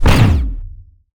energy_blast_small_02.wav